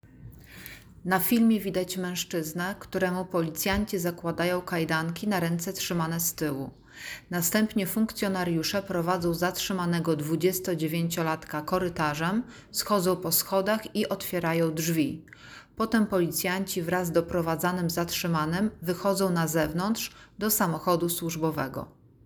Nagranie audio Audiodeskrypcja do filmu zatrzymany mężczyzna.